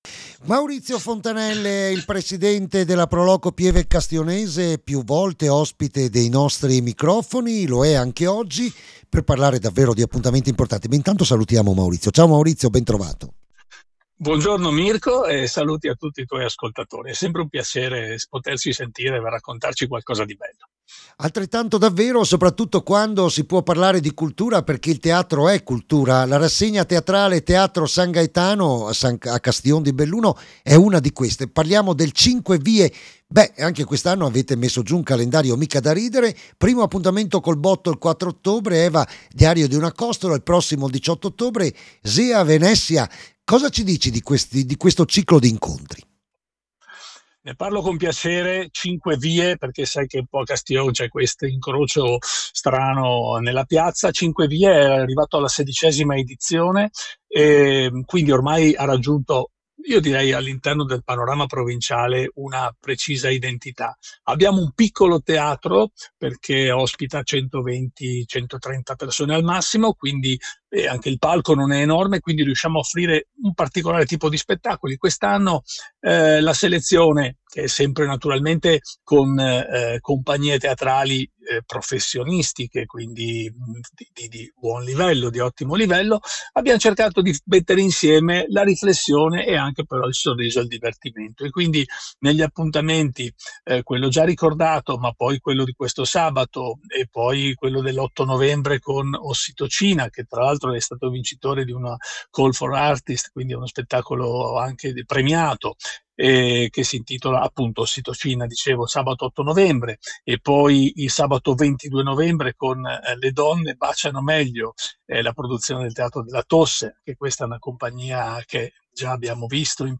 l’intervista.